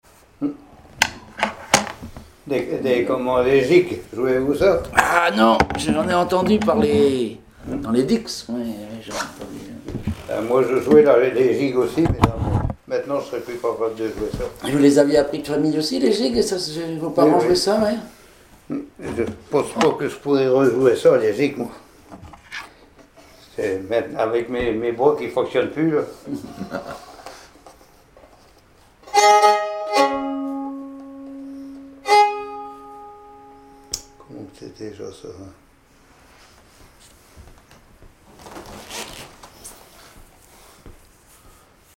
violoneux, violon,
Catégorie Témoignage